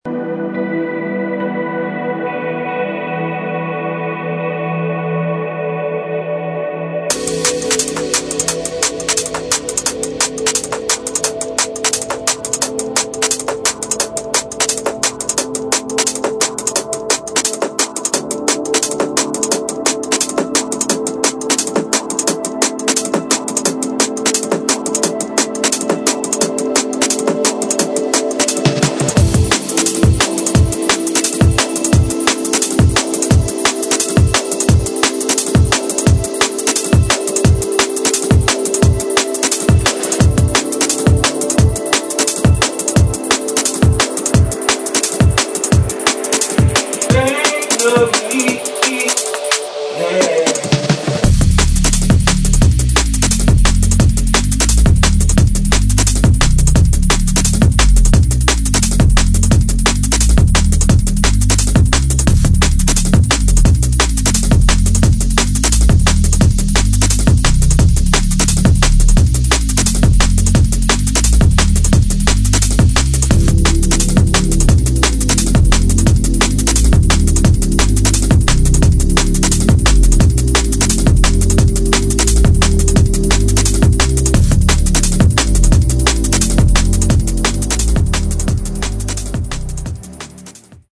[ DRUM'N'BASS / JUNGLE / BASS ]